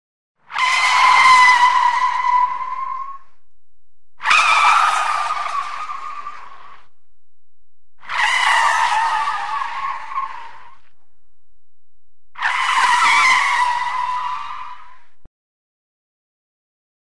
zvuki-avtomobilnoj-avarii_003
zvuki-avtomobilnoj-avarii_003.mp3